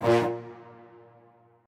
strings1_38.ogg